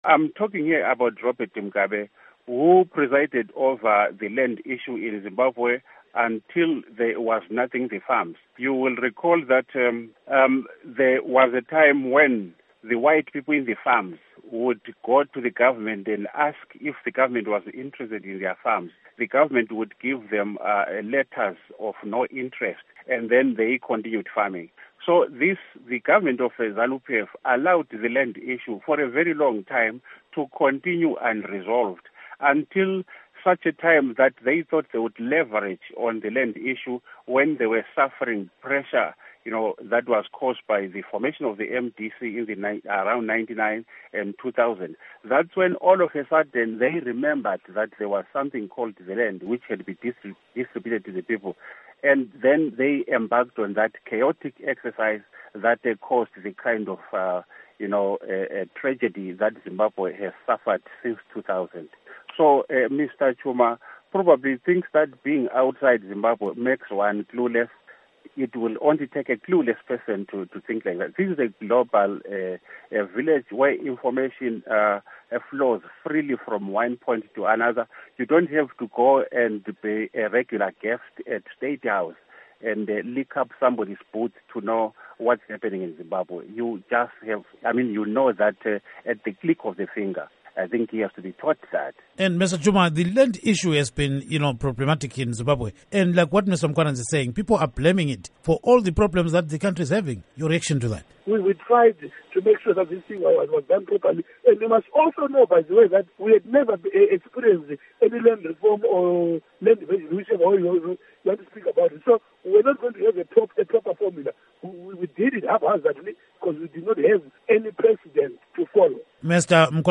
In a Studio 7 political panel
Interview